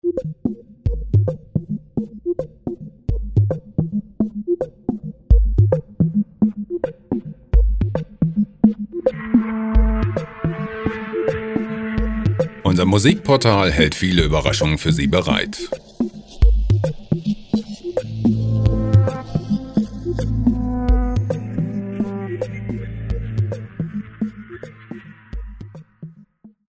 Minimal gemafrei
Musikstil: Glitch
Tempo: 108 bpm